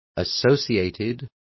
Complete with pronunciation of the translation of associated.